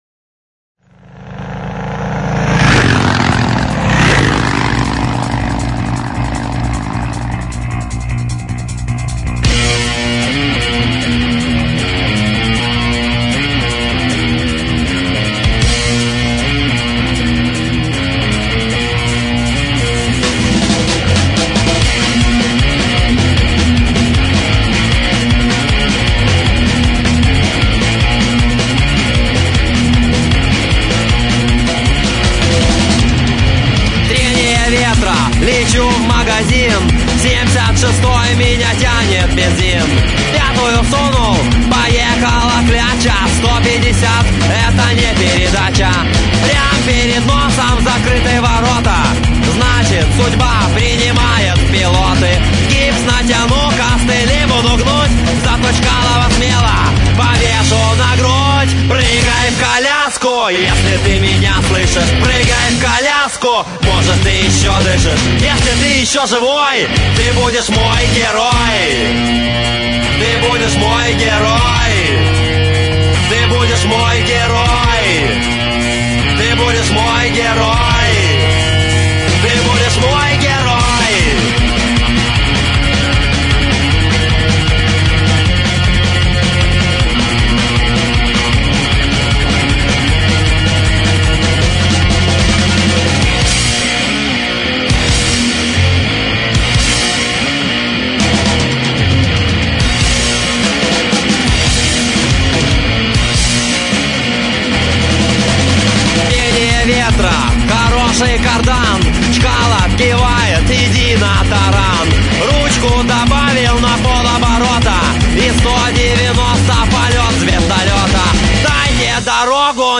Категория: Rok